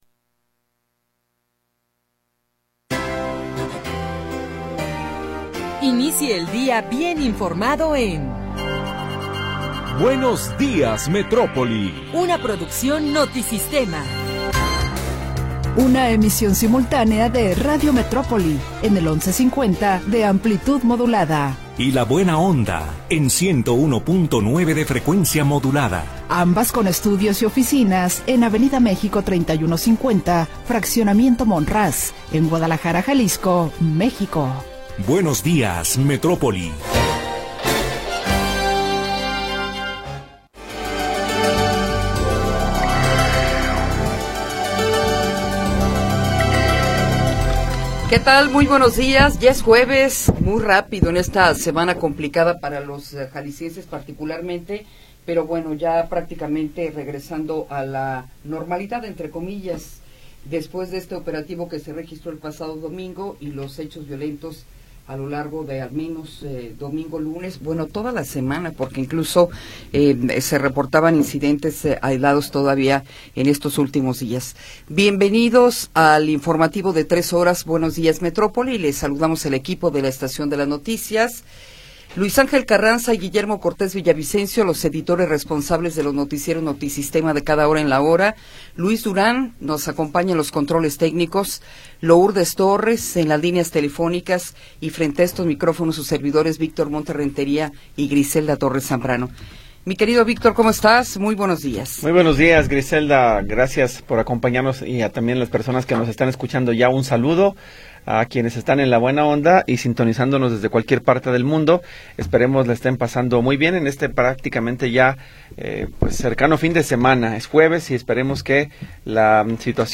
Información oportuna y entrevistas de interés
Primera hora del programa transmitido el 26 de Febrero de 2026.